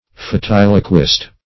Search Result for " fatiloquist" : The Collaborative International Dictionary of English v.0.48: Fatiloquist \Fa*til"o*quist\, n. [L. fatiloquus declaring fate; fatum fate+ Loqui to speak.]